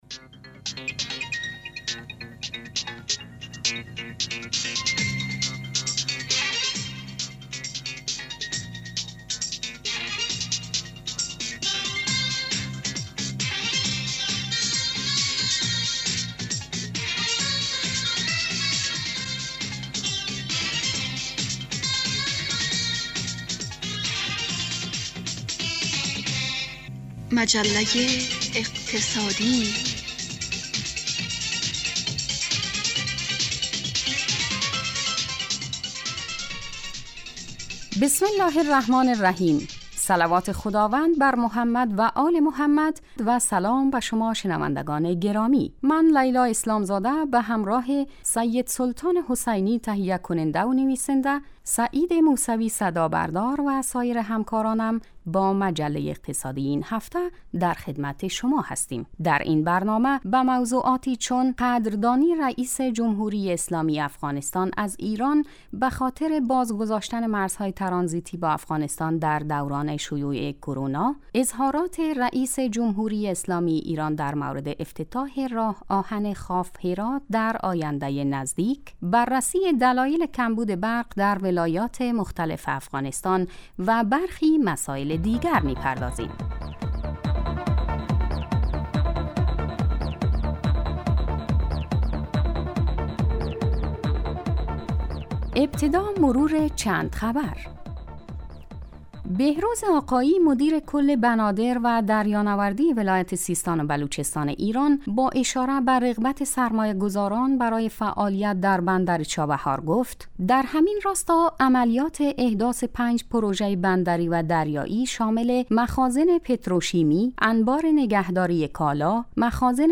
برنامه مجله اقتصادی به مدت 30 دقیقه روز جمعه در ساعت 01:30 ظهر (به وقت افغانستان) پخش می شود. این برنامه به بررسی رویدادهای اقتصادی افغانستان می پردازد....